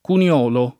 [ kun L0 lo ]